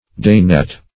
Day-net \Day"-net`\ (-n[e^]t`), n. A net for catching small birds.